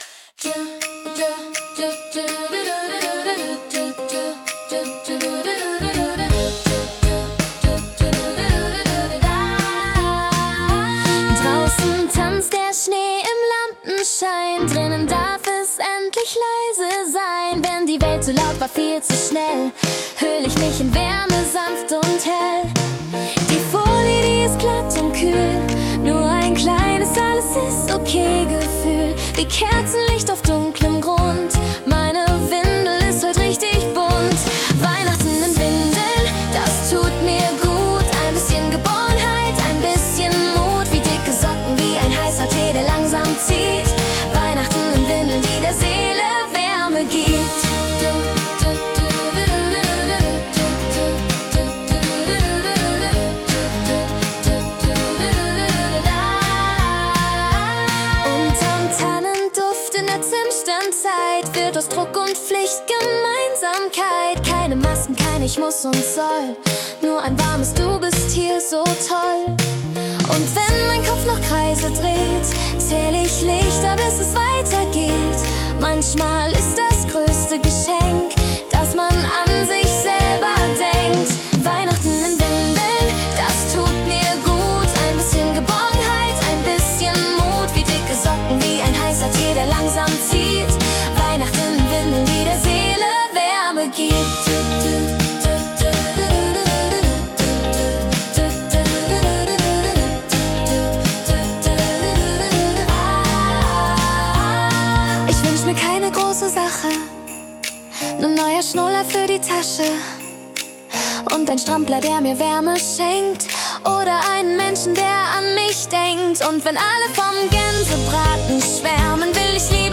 ein berührender, sanftmütiger Weihnachtssong